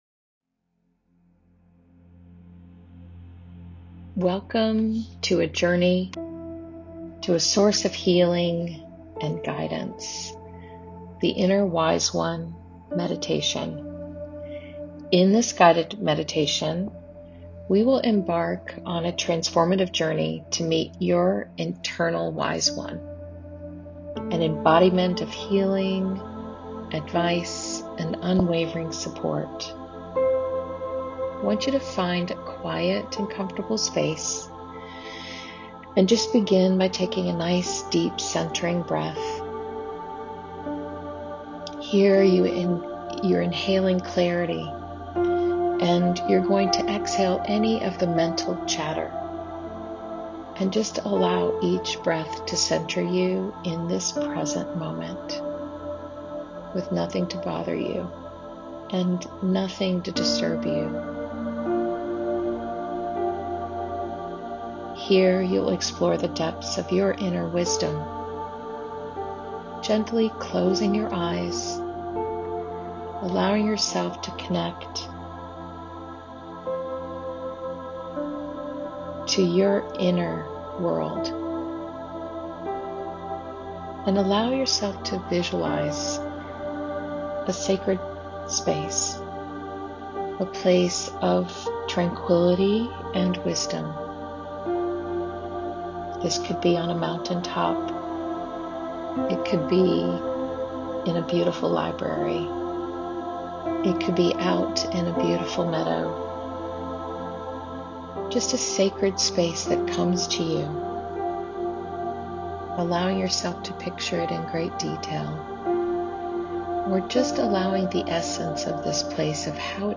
This meditation guides you to listen to your inner wisdom, cultivating self-trust and healing through deep reflection and insight.